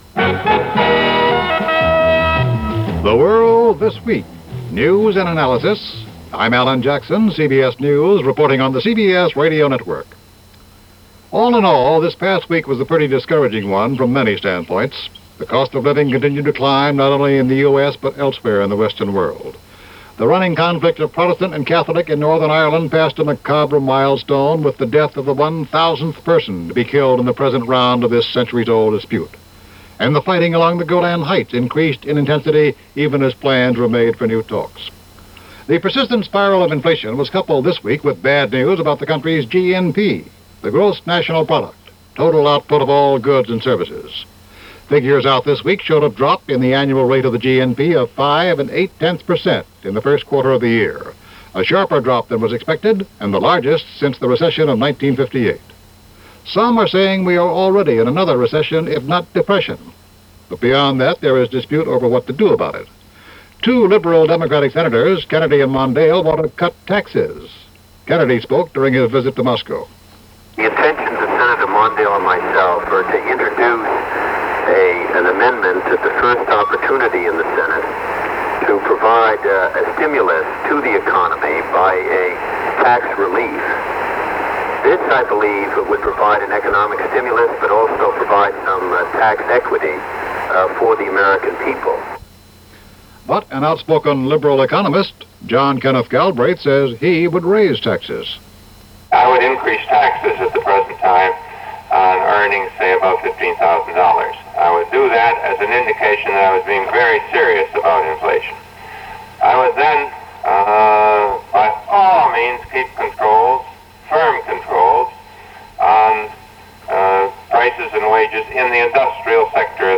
And with the continuing story in Northern Ireland and The Middle-East, that’s just a little of what happened the week ending April 21, 1974 as reported by CBS Radio’s The World This Week.